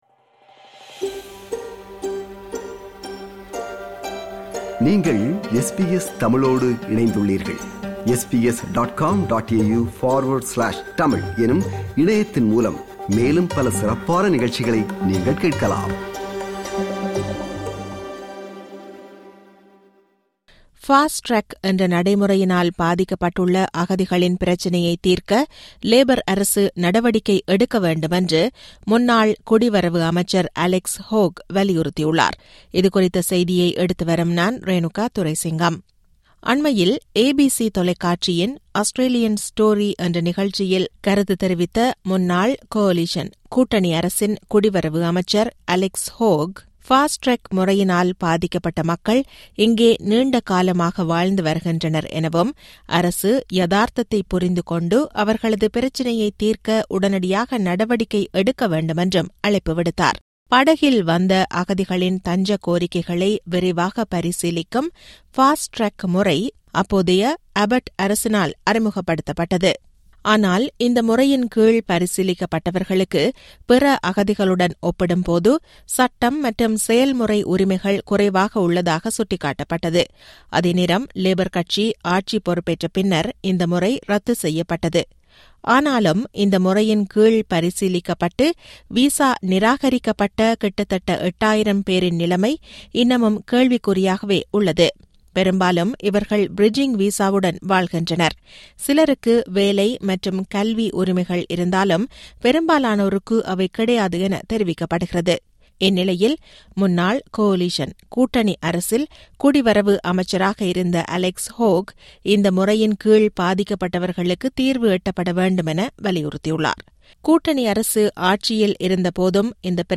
Fast Track என்ற நடைமுறையினால் பாதிக்கப்பட்டுள்ள அகதிகளின் பிரச்சினையை தீர்க்க லேபர் அரசு நடவடிக்கை எடுக்க வேண்டும் என முன்னாள் குடிவரவு அமைச்சர் Alex Hawke வலியுறுத்தியுள்ளார். இதுகுறித்த செய்தியை எடுத்துவருகிறார்